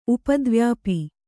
♪ upadvyāpi